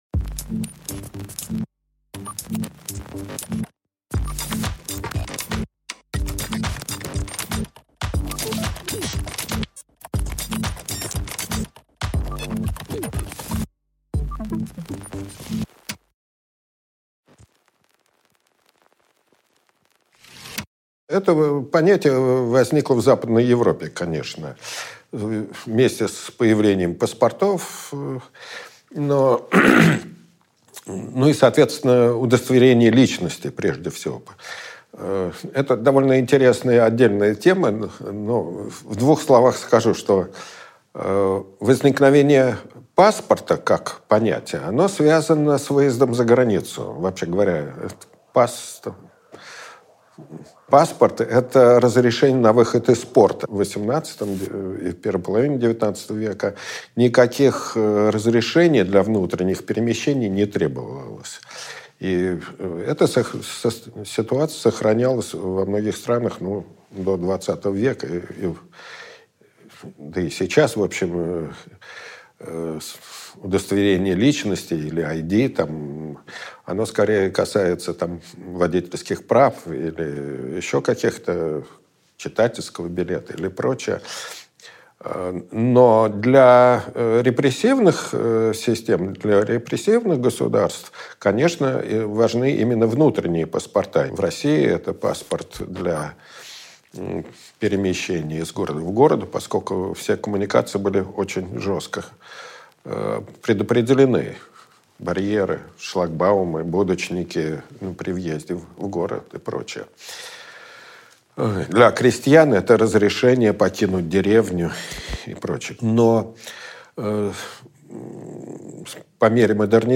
Аудиокнига Пора определиться | Библиотека аудиокниг